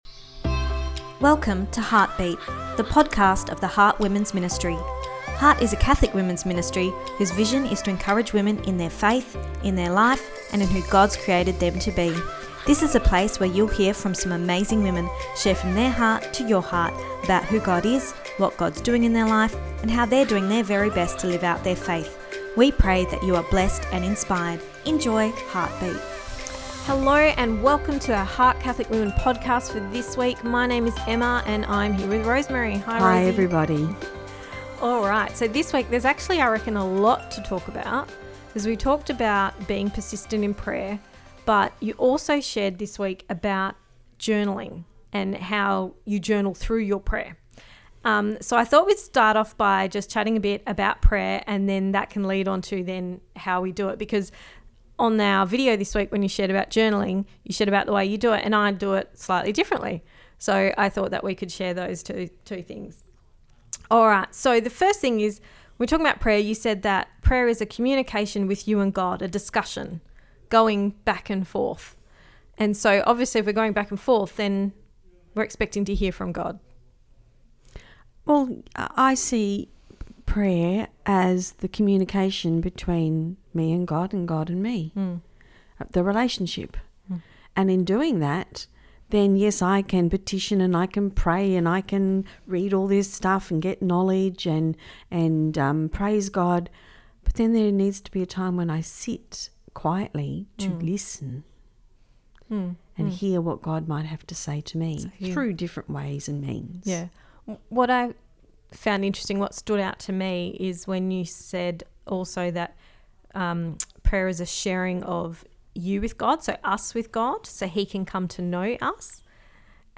episode-29-are-you-persistent-part-2-the-discussion.mp3